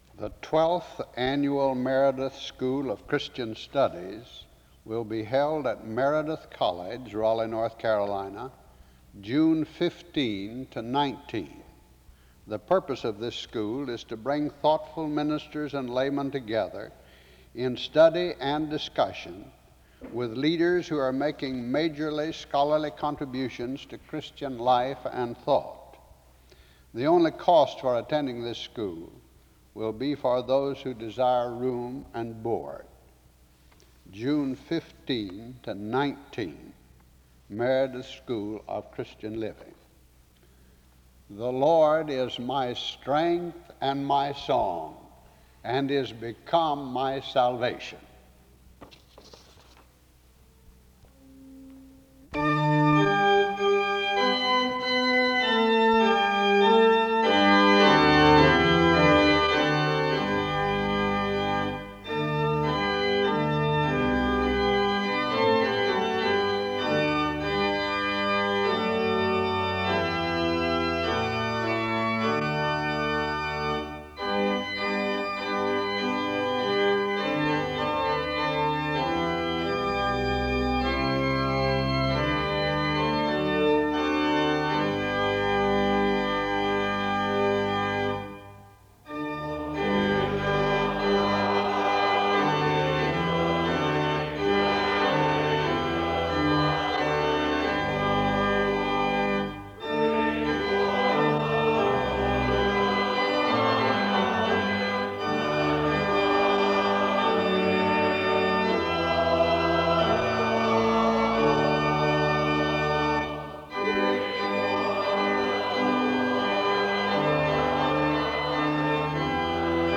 The service opens with announcements and music from 0:00-2:07. A prayer is offered from 2:08-2:52. The source text for the sermon, John 3:14-19, was read from 3:00-3:56. Music plays from 4:04-7:32.
Closing music plays from 25:07-28:29. A closing prayer is made from 28:31-28:58.